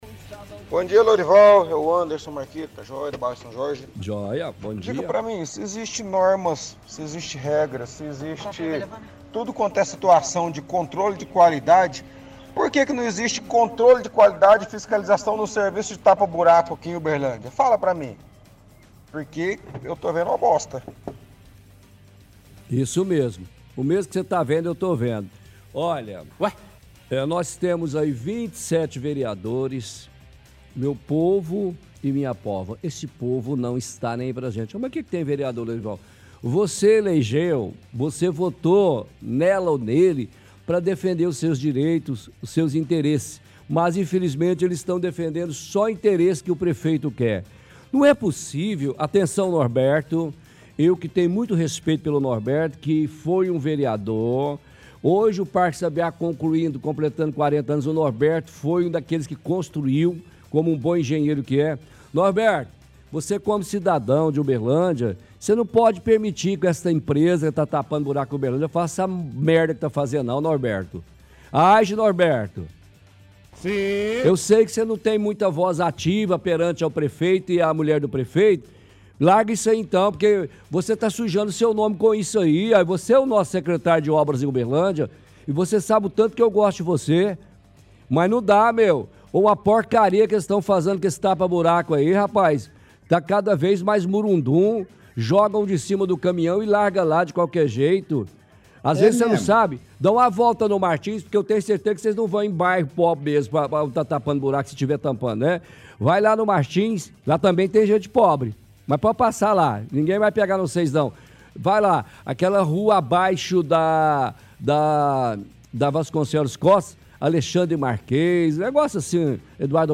– Ouvinte reclama de falta de controle de qualidade na operação tapa buracos.